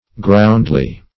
Search Result for " groundly" : The Collaborative International Dictionary of English v.0.48: Groundly \Ground"ly\, adv.